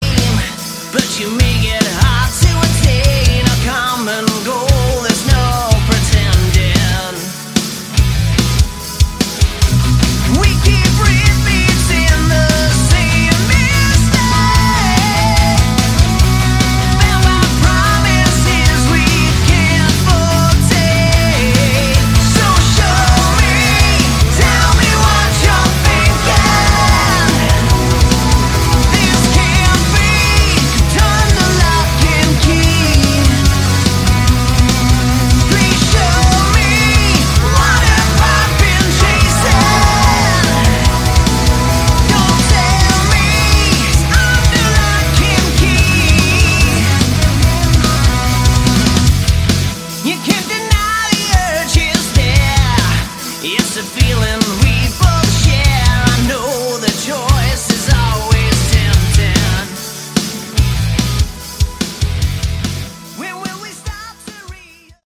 Category: AOR
vocals
keyboards, piano, percussion
guitar, acoustic guitar
bass, acoustic guitar